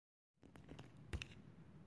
描述：Cae（hielo）
标签： 环境 atmophere 记录
声道立体声